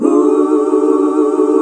HUH SET D.wav